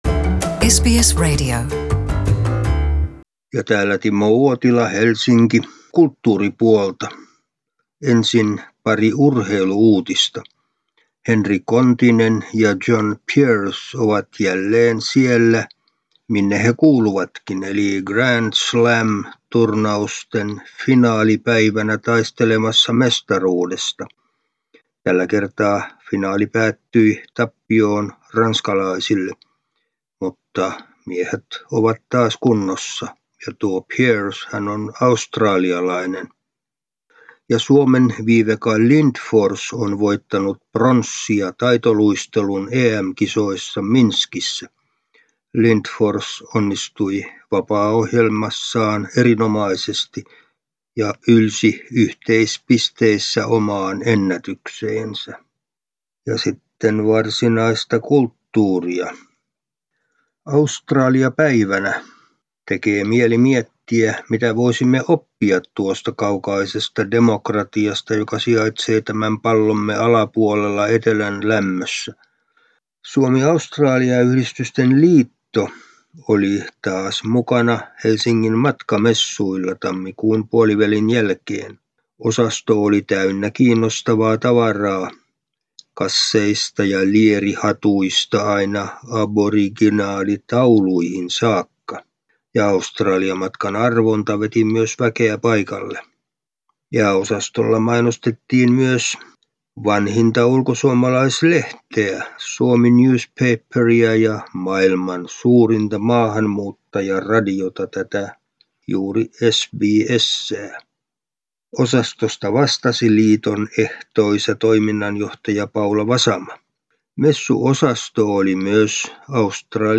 urheilu- ja kulttuuriraportti